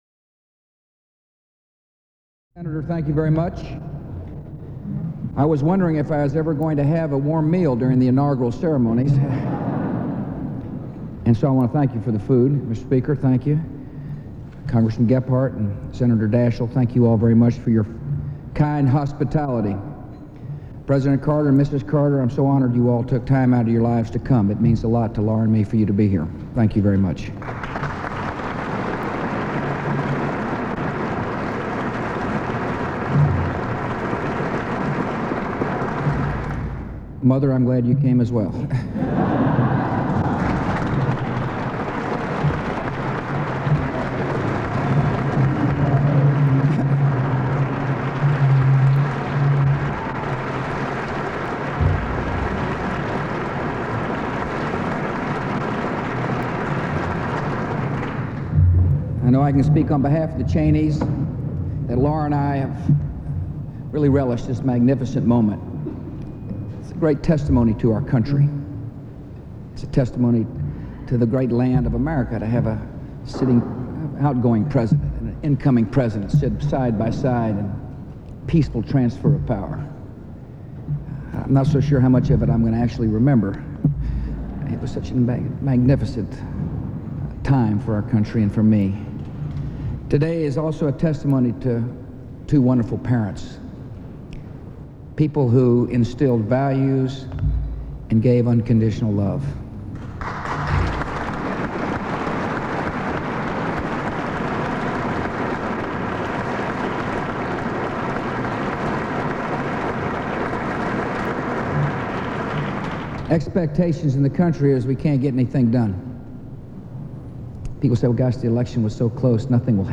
U.S. President George W. Bush speaks at a post-inauguration congressional luncheon